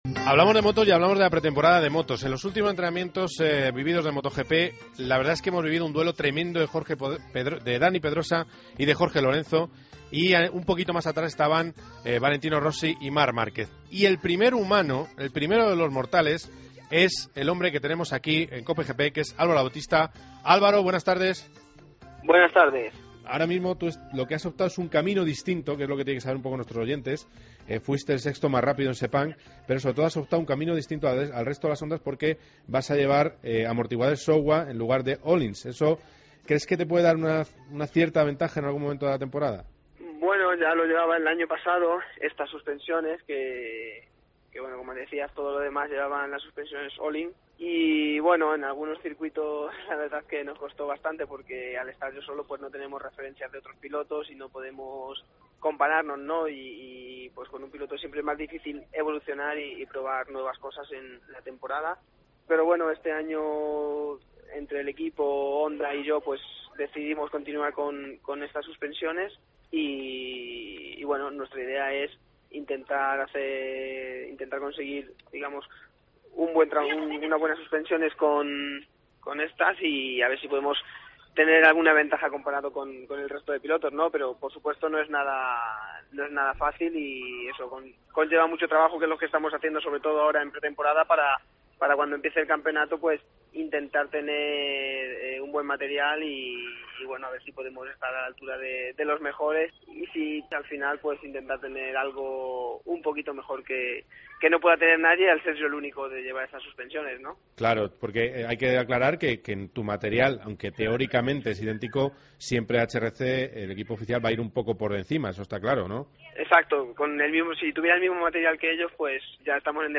Entrevista a Álvaro Bautista, piloto de Moto GP